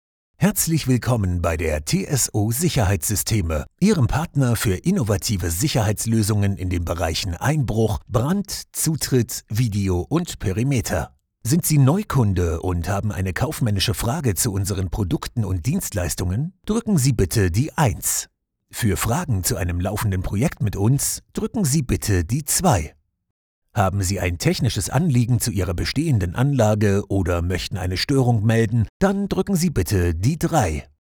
Naturelle, Mature, Chaude, Cool, Profonde
Téléphonie
Toutes les voix-off sont enregistrées dans un studio équipé de manière professionnelle.